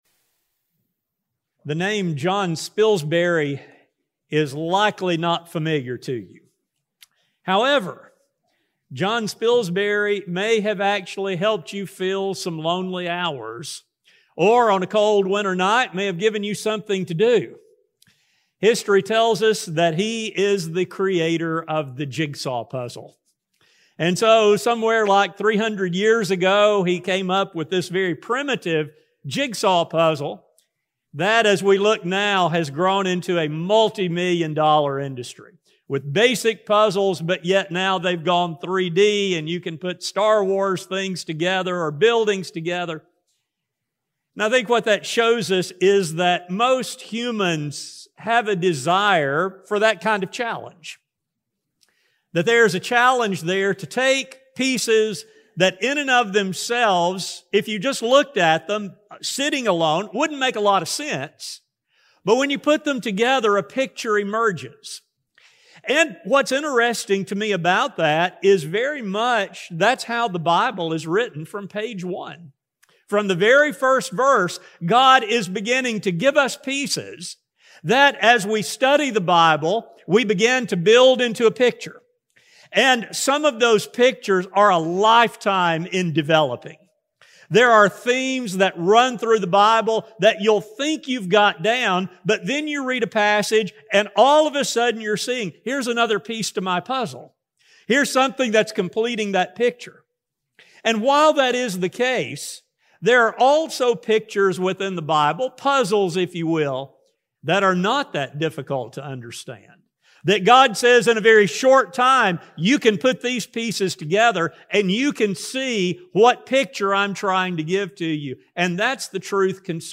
This lesson will examine the pieces of the salvation puzzle and how they can be put together for a full understanding of God’s love and His willingness to offer grace and mercy to those who will accept His love. A sermon recording